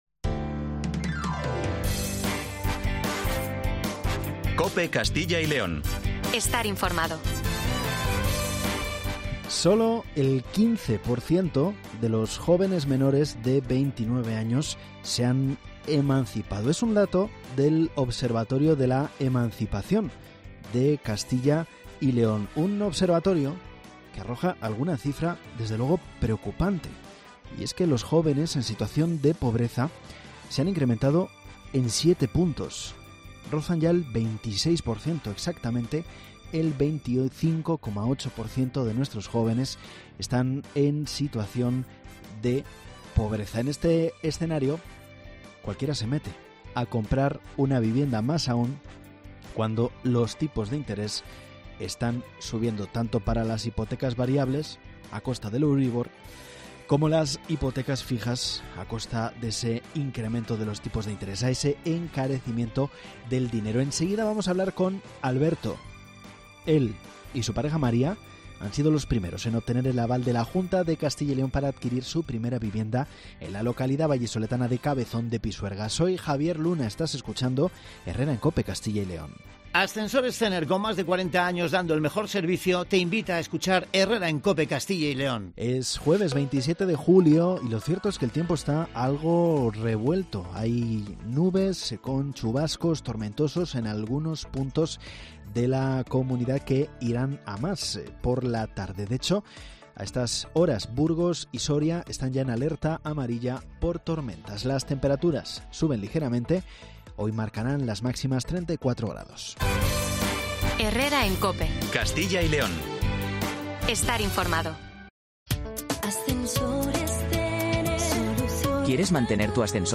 Valladolid - Tordesillas - Iscar Herrera en Cope Castilla y León (27/07/2023) Los avales de la Junta de Castilla y León para la adquisición de primera vivienda ya están en marcha. Hablamos con los primeros en obtenerla, una pareja de Valladolid.